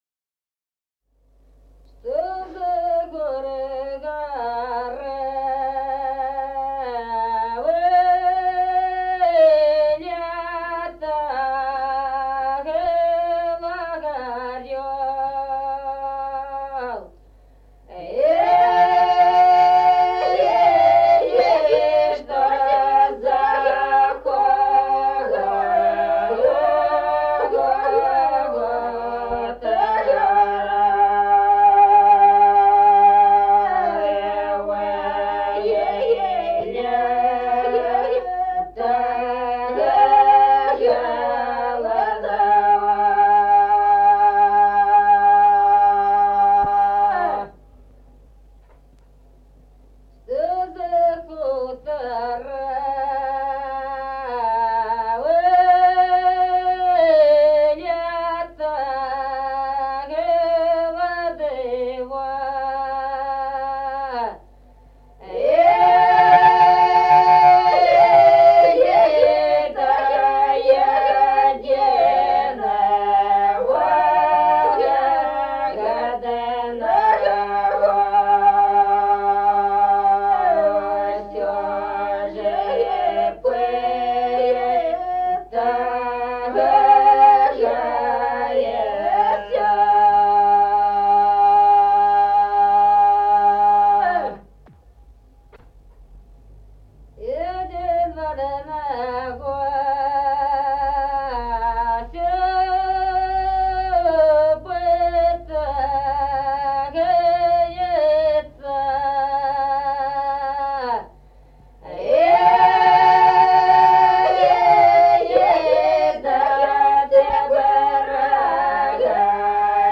Песни села Остроглядово. Что из-за гор-горы вылетал орел.